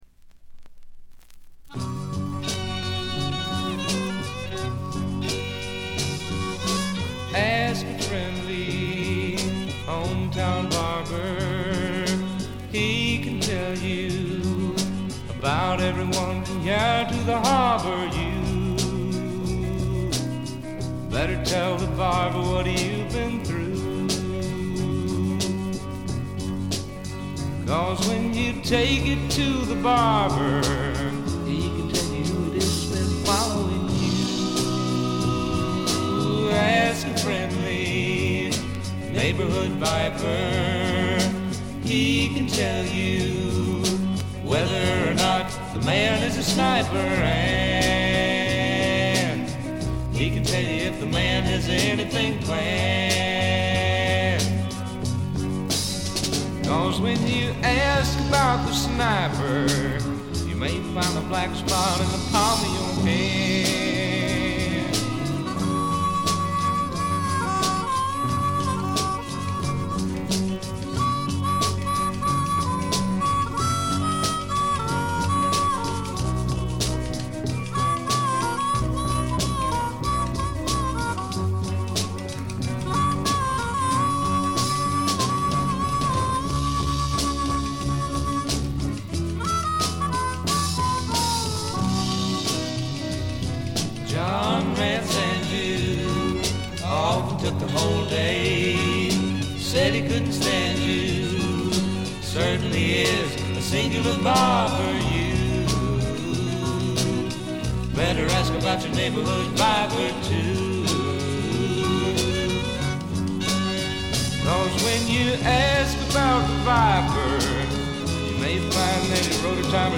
静音部での軽微なバックグラウンドノイズ程度。
素晴らしいサイケデリック名盤です。
試聴曲は現品からの取り込み音源です。
Recorded At - Sound City Inc, Recording Studios